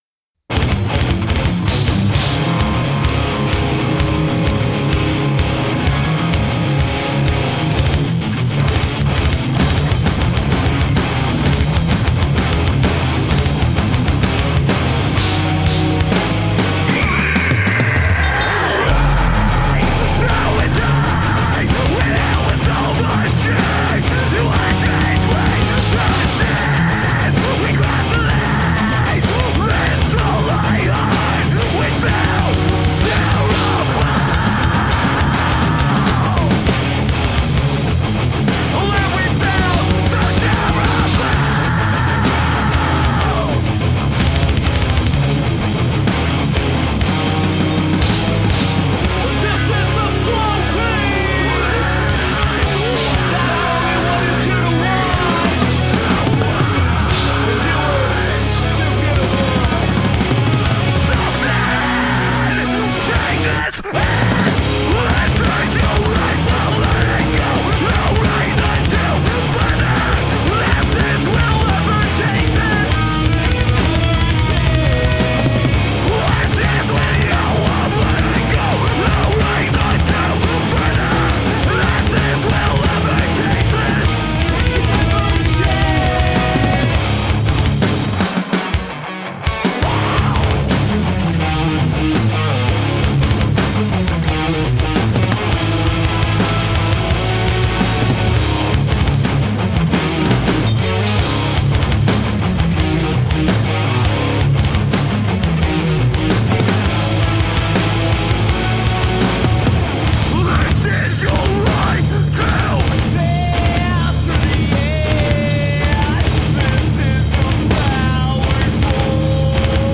Tagged as: Hard Rock, Metal, Intense Metal